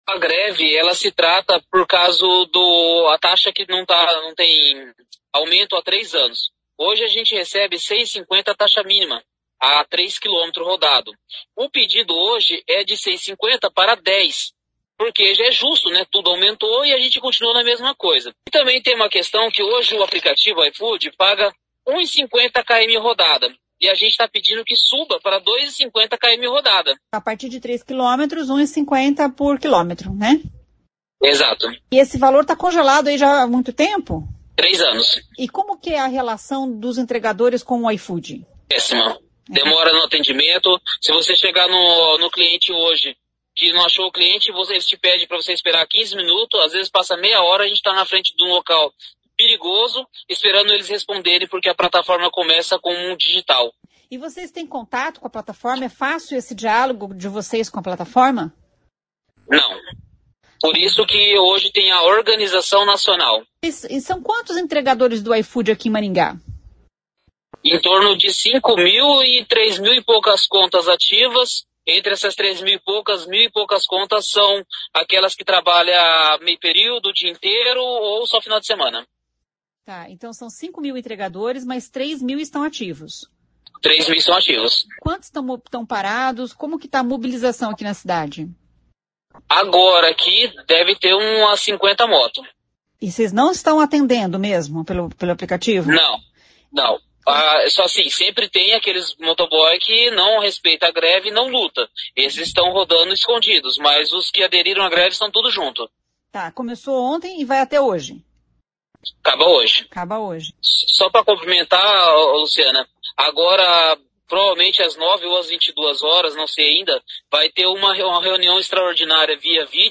Um dos manifestantes em greve